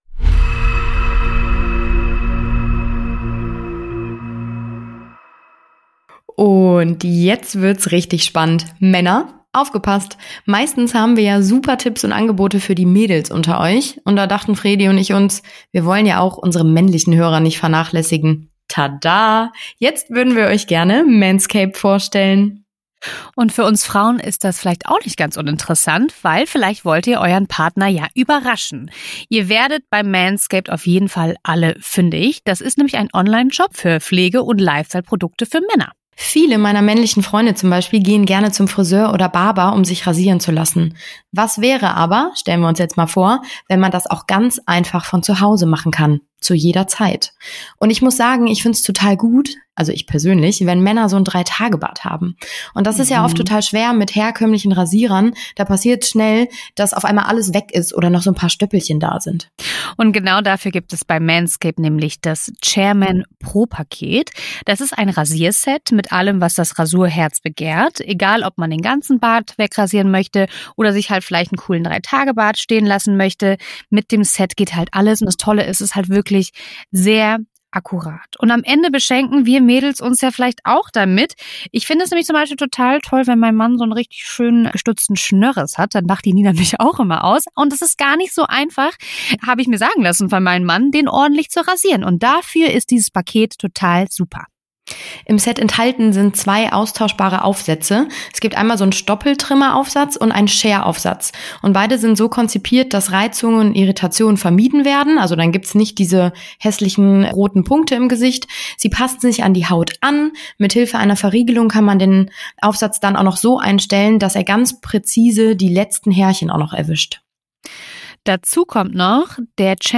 Bei uns wird auch mal gelacht, getratscht und Umgangssprache verwendet.*